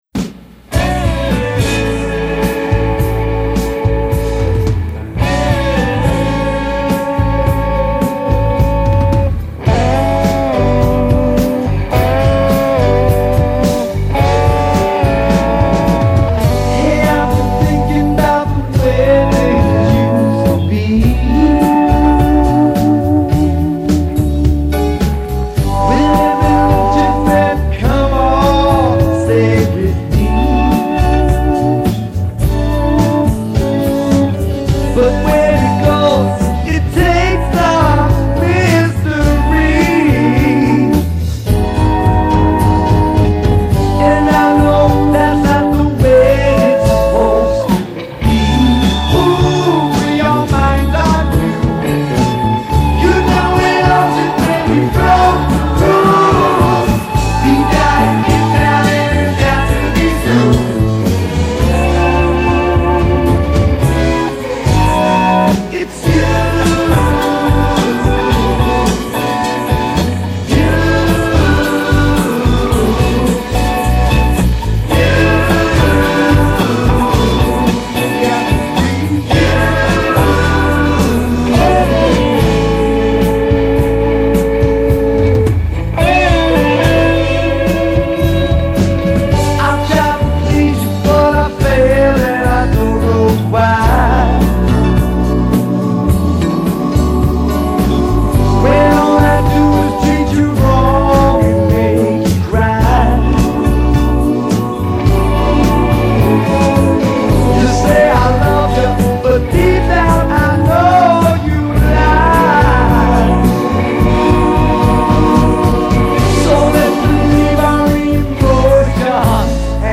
2010 Guitar
lead vocals
bass guitar
drums
Piano